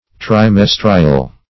Meaning of trimestrial. trimestrial synonyms, pronunciation, spelling and more from Free Dictionary.
trimestrial.mp3